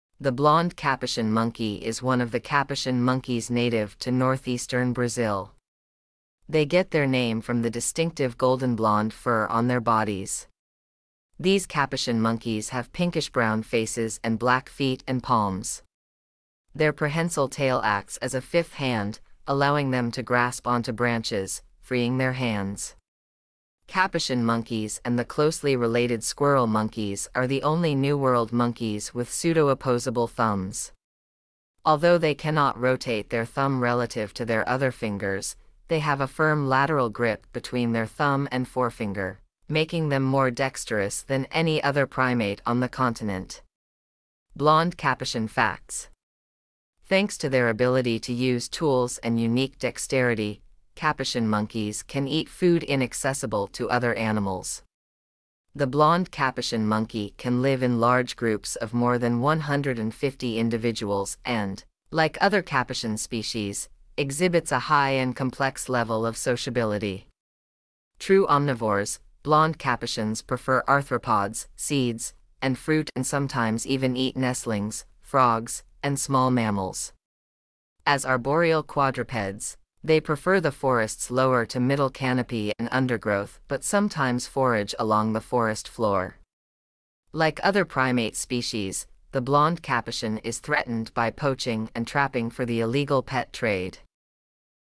Blond Capuchin
blond-capuchin.mp3